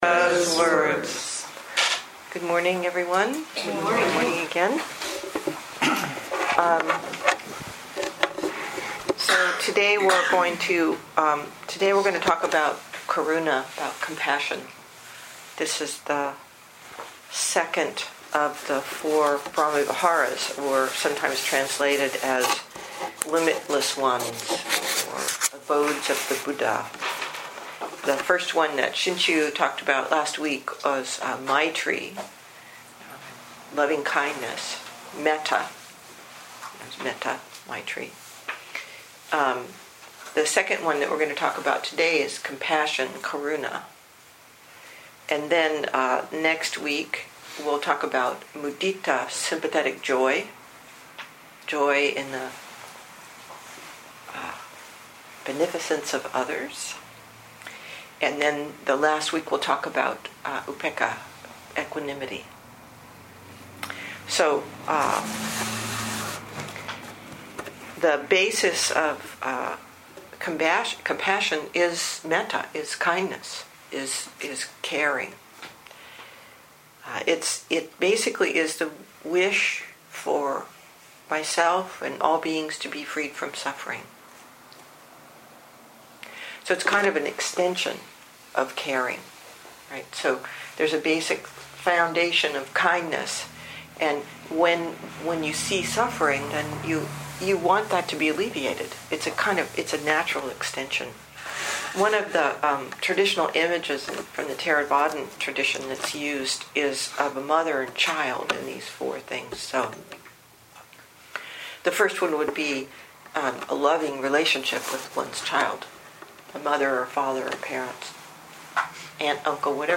2015 in Dharma Talks